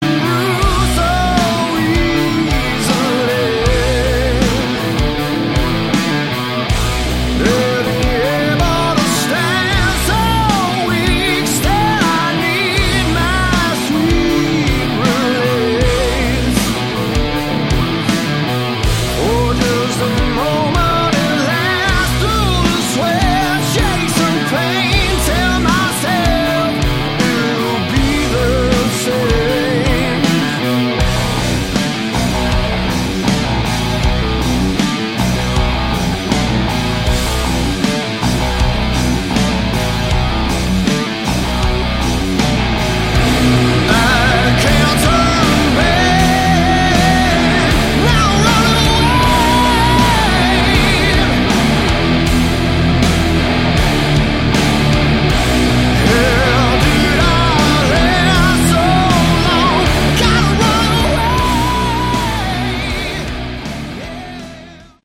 Category: Hard Rock
guitar
bass
vocals
drums